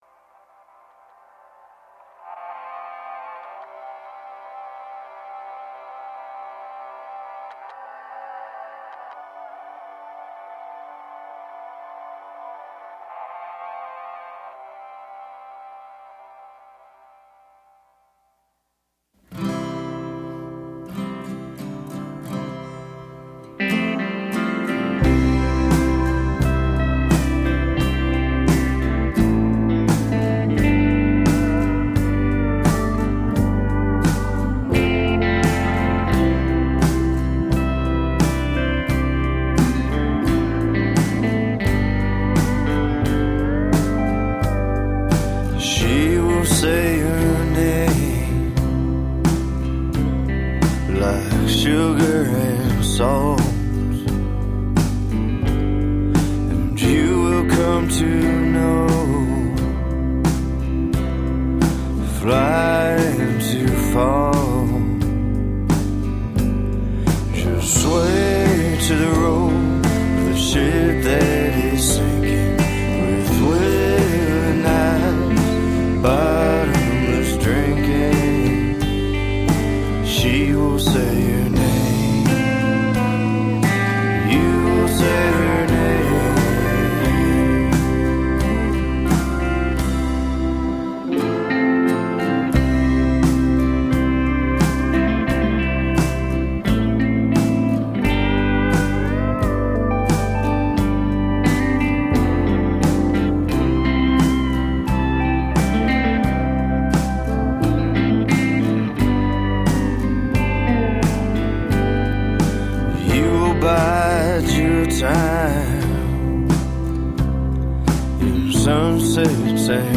Darkly gorgeous, cinematic folk.
Every tune is bathed in the steep shadow of romantic sorrow
electric guitar is soulfully rippled
cello and violin arrangements
lap steel